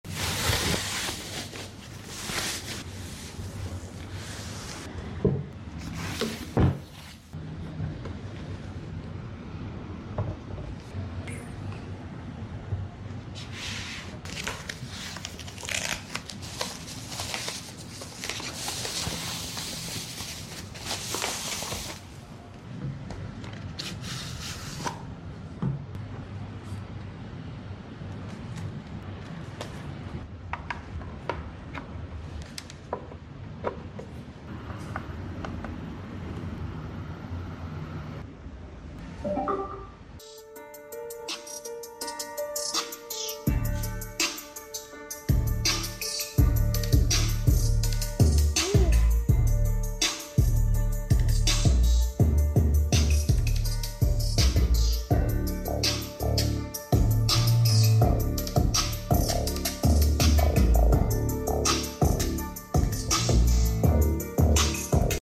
590W Soundbar with Deep Bass sound effects free download
590W Soundbar with Deep Bass & Atmos! 🔊 JBL Bar 500 Sound Testing.